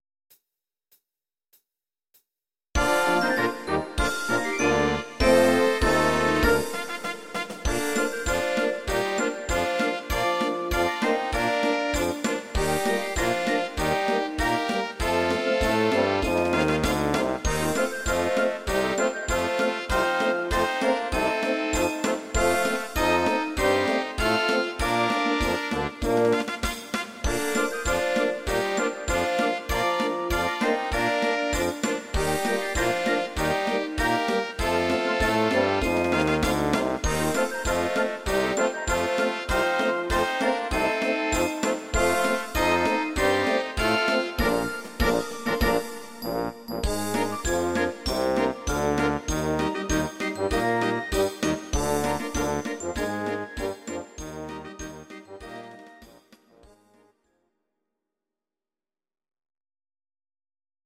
These are MP3 versions of our MIDI file catalogue.
Your-Mix: Jazz/Big Band (731)